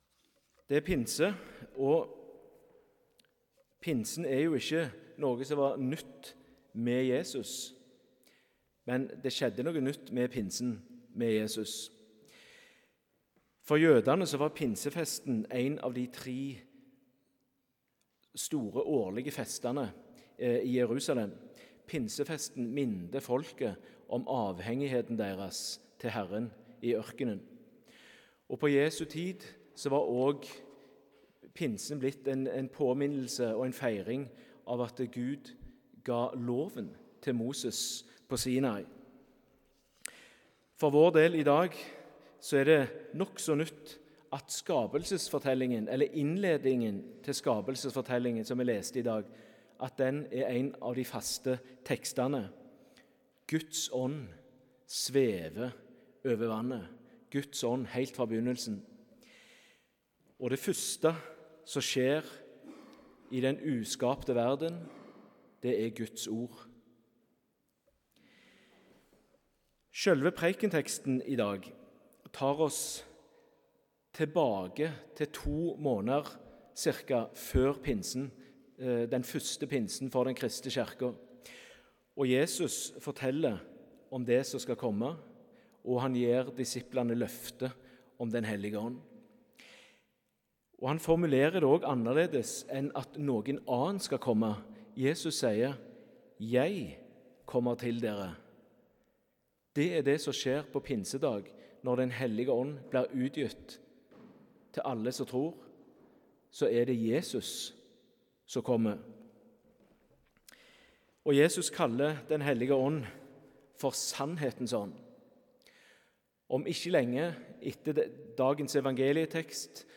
19. mai 2024 – pinsedag